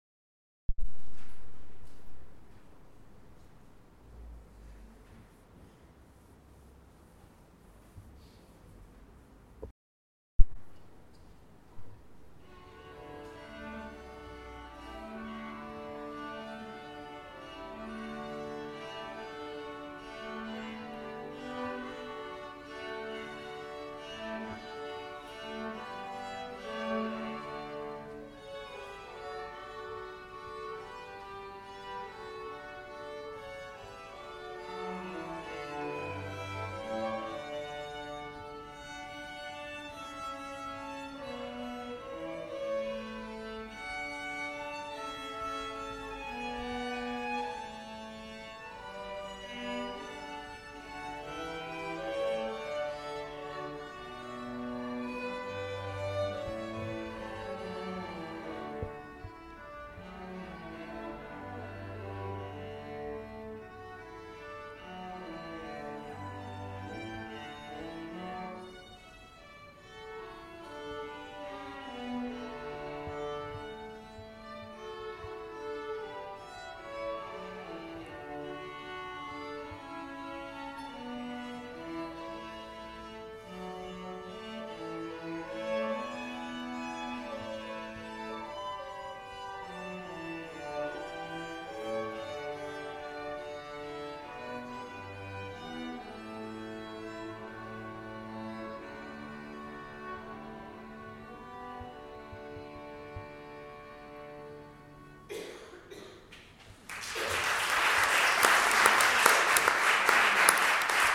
quintet
Strings Concert Feb 2015